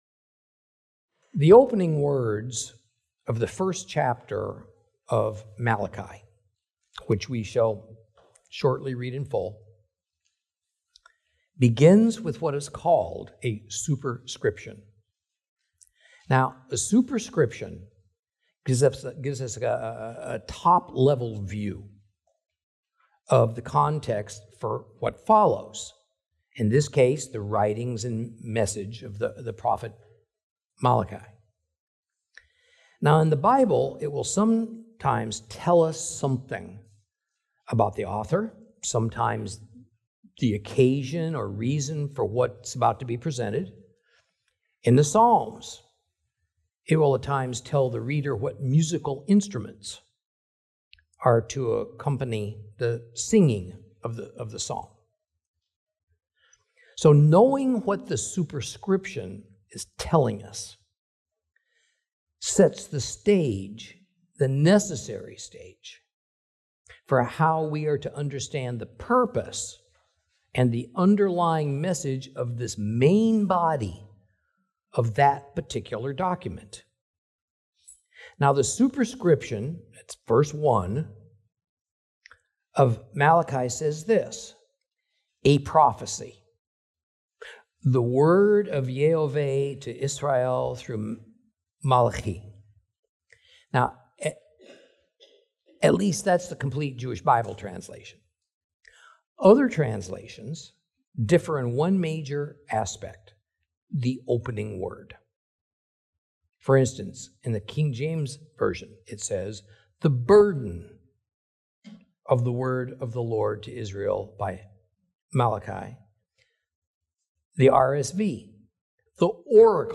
Teaching from the book of Malachi, Lesson 2 Chapter 1.